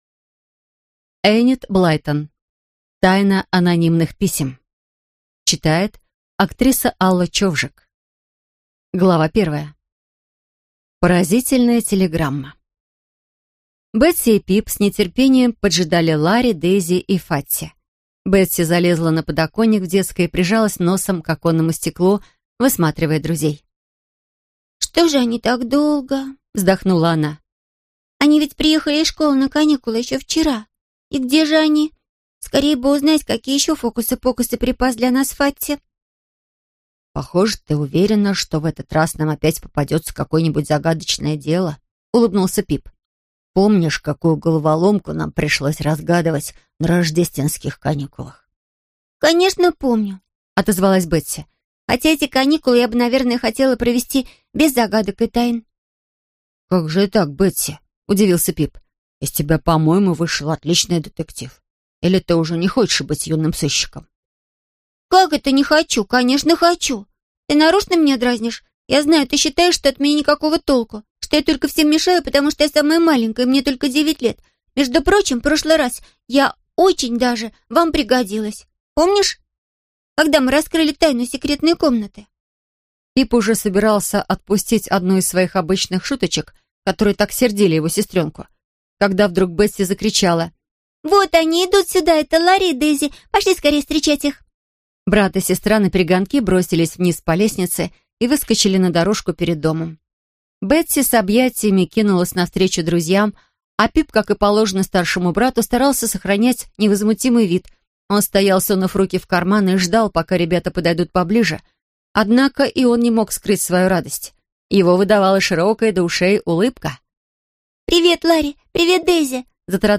Аудиокнига Тайна анонимных писем | Библиотека аудиокниг